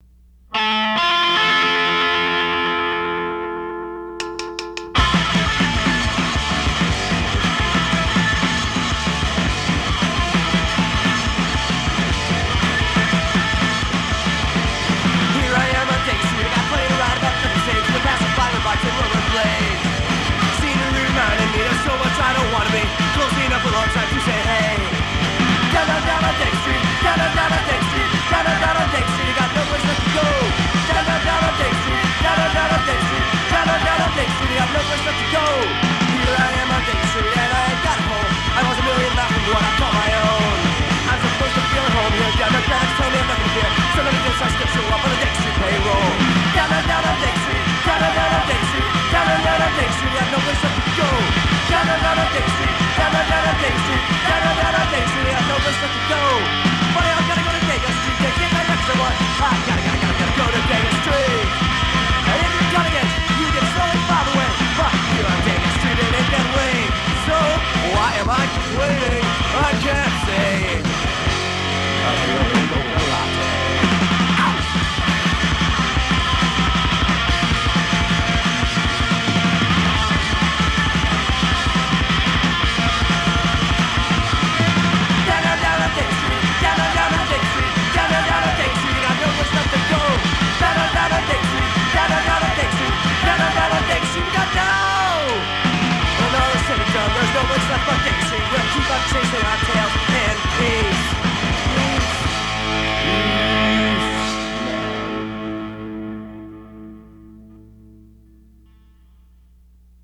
Guitar/Vocals
Drums
Bass/Backing Vocals
Pop-Punk